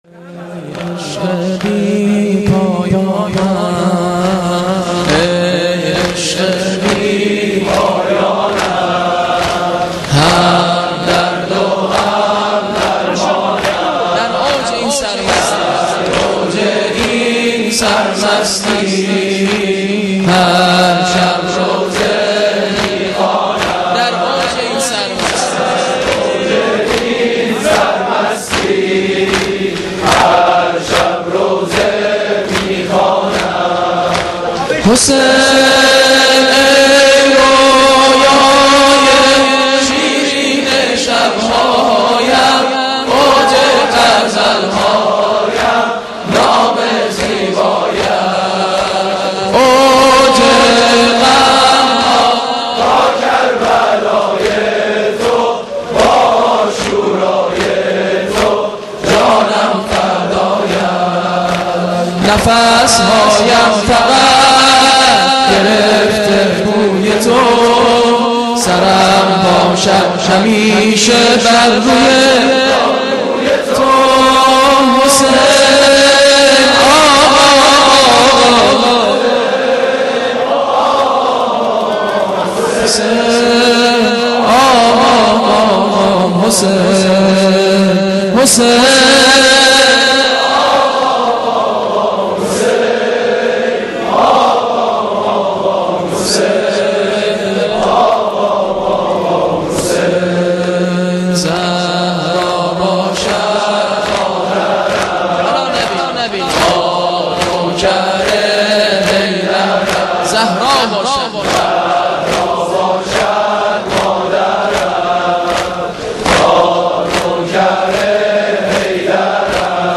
سرود پایانی هیات محبین اهل بیت دانشگاه هنر تهران + متن و صوت